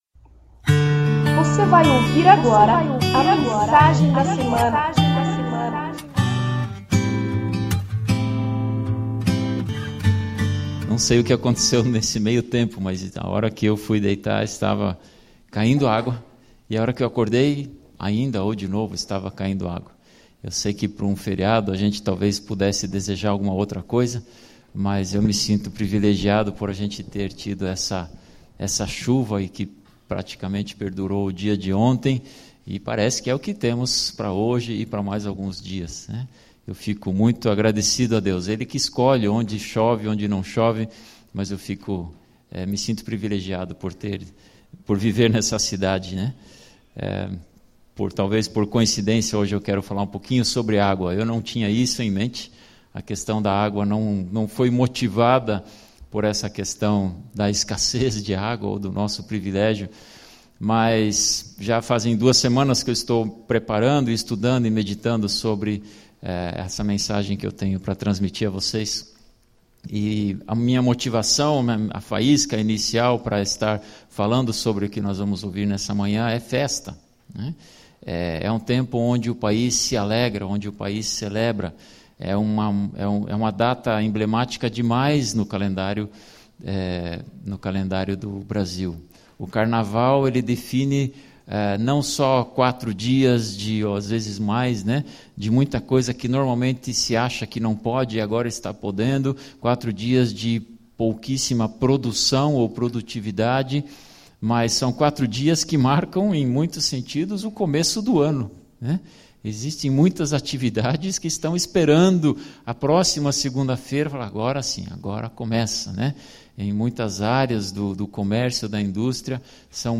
Mensagem ministrada no dia 15/02/2015.